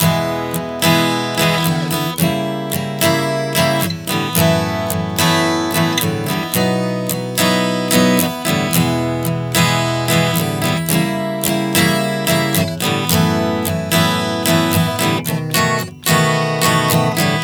Prog 110 E-C#°-F#m [F#m-B].wav